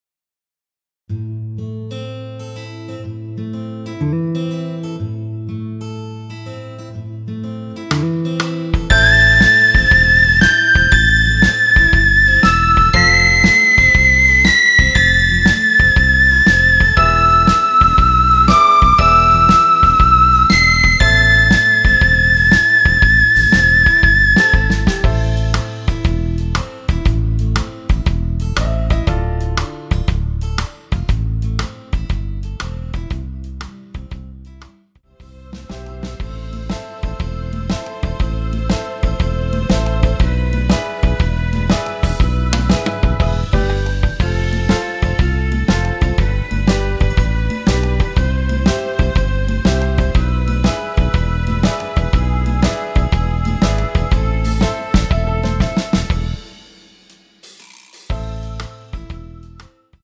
엔딩이 페이드 아웃으로 끝나서 라이브에 사용하실수 있게 엔딩을 만들어 놓았습니다.
원키에서(+5)올린 여성분이 부르실수 있는 키의 MR입니다.
Am
앞부분30초, 뒷부분30초씩 편집해서 올려 드리고 있습니다.
중간에 음이 끈어지고 다시 나오는 이유는